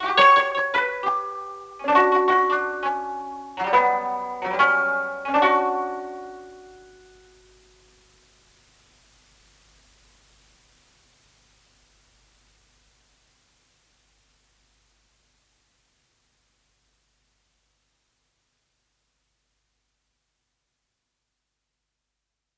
ThePhonoLoops Cassette Plucks.01是一款结合了物理建模、一些减法合成和采样的卡带系列乐器库。它包含了两种不同的带有东方风情的拨弦乐器——都是从头设计的²。由于制作每一层的时候都很用心，再加上老式卡带录音机的魔力，ThePhonoLoops Cassette Plucks.01是另一款低保真和美味的声音集合，带有各种小的不完美之处。
ThePhonoLoops Cassette Plucks.01使用4种不同的卡带录制了全音域（5个八度）的4个动态层。